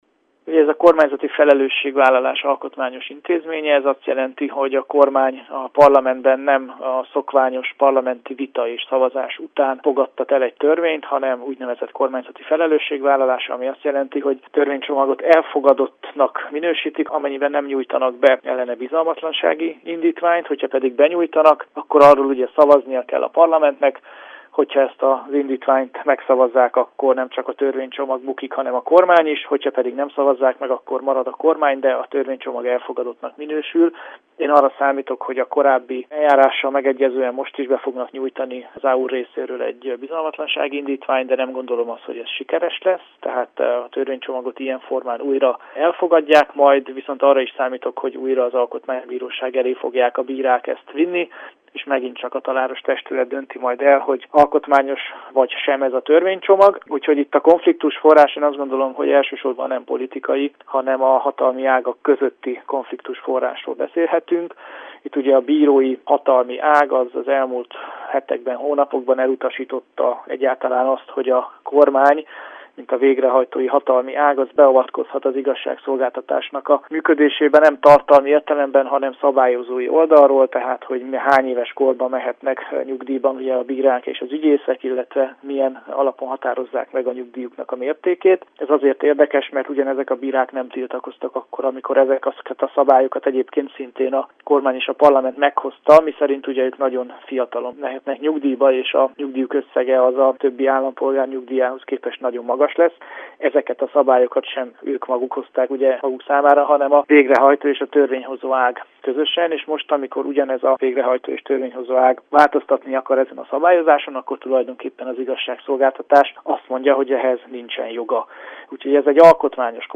Politikai elemző nyilatkozott rádiónknak a jelenlegi kormány koalíció idei munkájáról, valamint a jövőbeli kilátásokról.